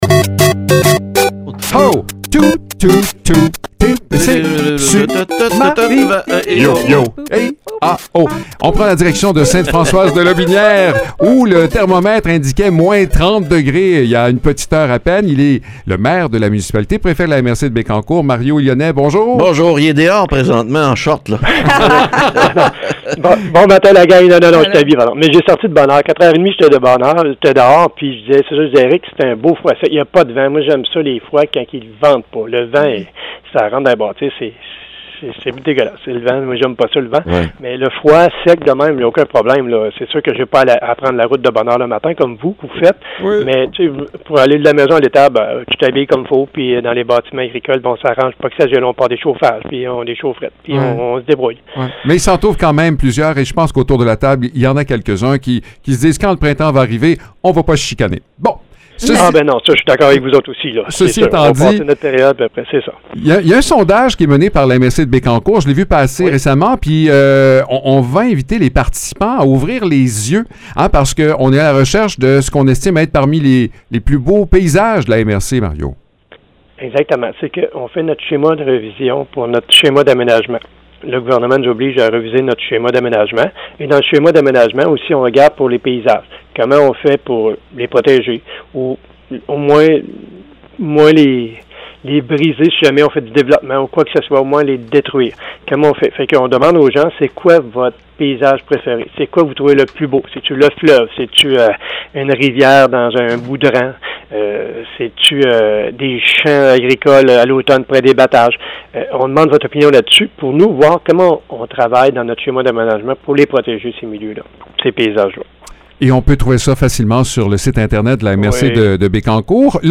Mario Lyonnais, maire de Sainte-Françoise et préfet de la MRC de Bécancour, nous parle d’un sondage en ligne lancé afin d’identifier les plus beaux paysages de Bécancour, tels que vus par les citoyens.